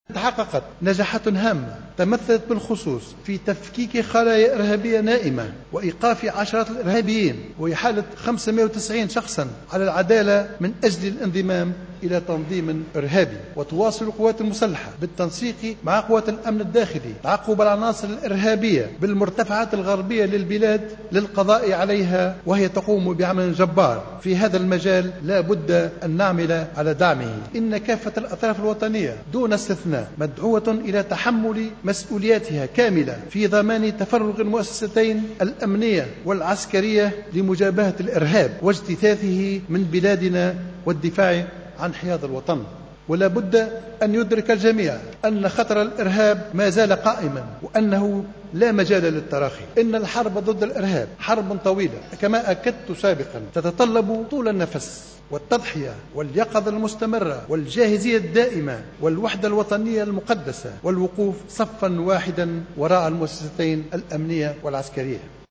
كشف رئيس الحكومة الحبيب الصيد في كلمة ألقاها اليوم الجمعة 5 جوان 2015 في مجلس نواب الشعب أنّه تمت إحالة 590 شخصا على العدالة بتهمة الانتماء إلى تنظيم إرهابي.